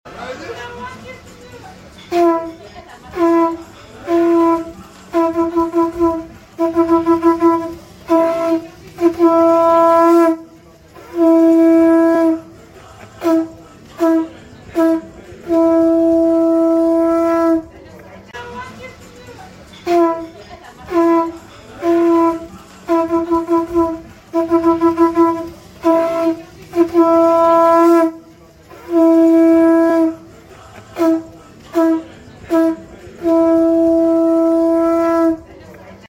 You must hear this chef playing a seashell horn